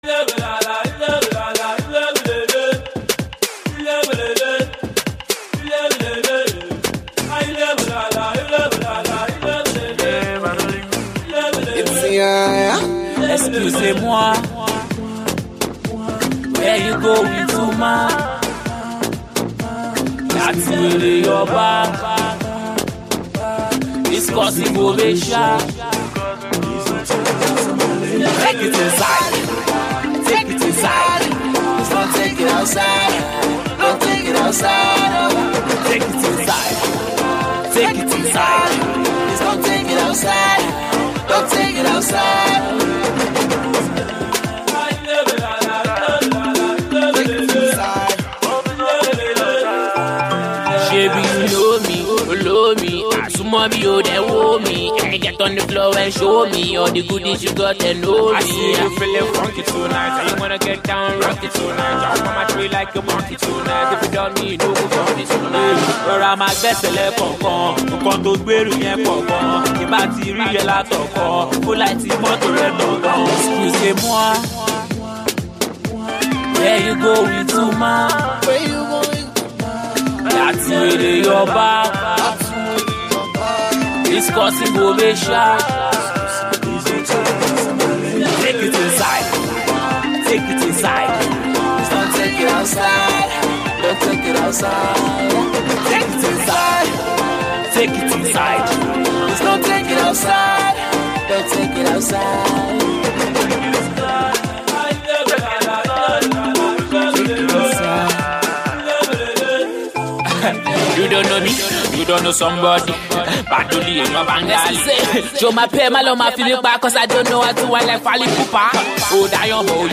rap star